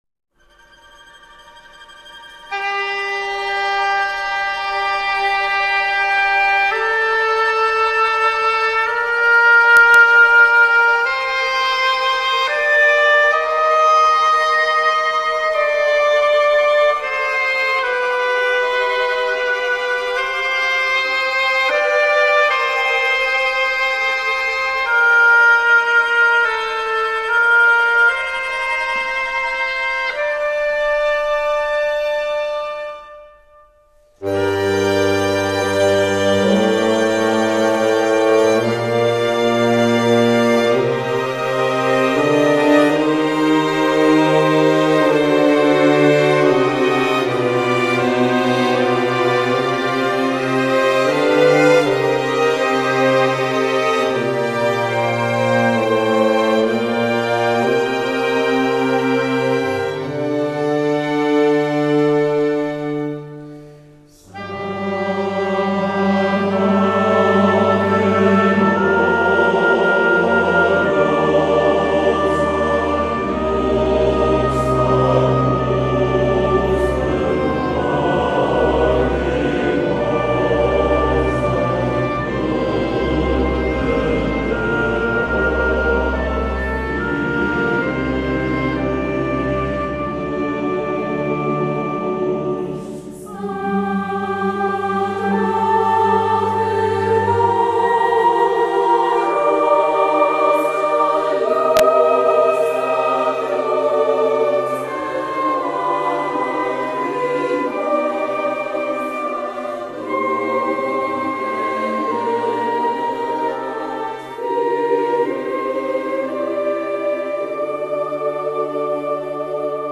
PREKRÁSNY KONCERT
stará sakrálna hudba
miešaného speváckeho zboru